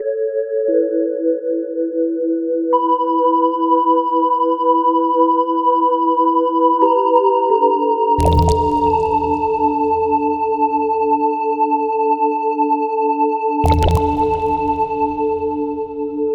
ループ：◎
BPM：88 キー：Gm ジャンル：ゆったり 楽器：シンセサイザー